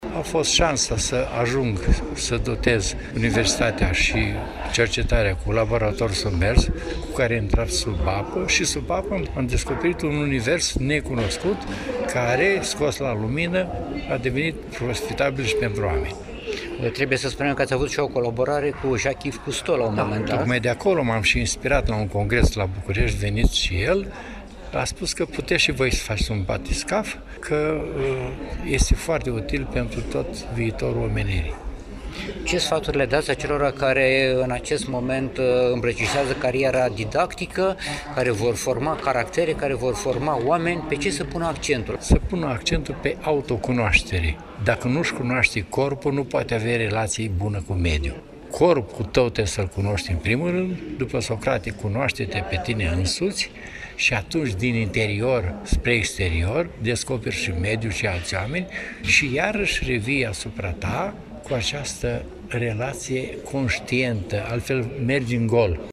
în cadrul unei ceremonii organizate în Sala Senatului a Universității „Alexandru Ioan Cuza” din Iași.